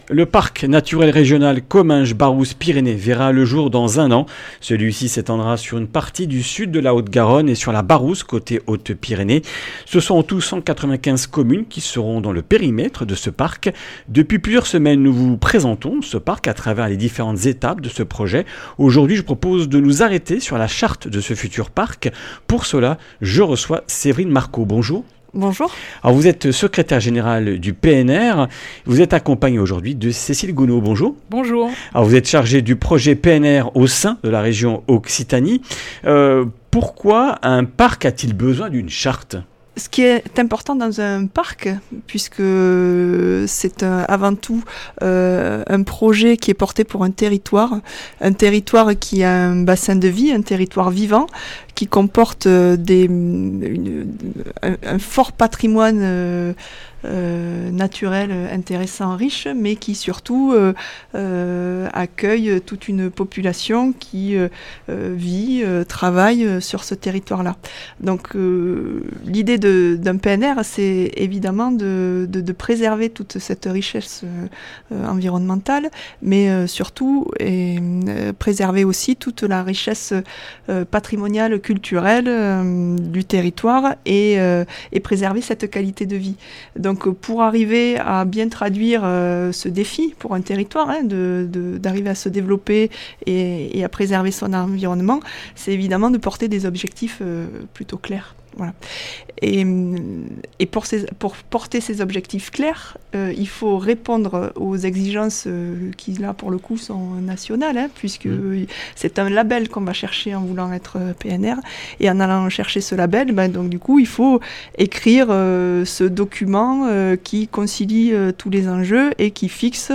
Comminges Interviews du 19 mars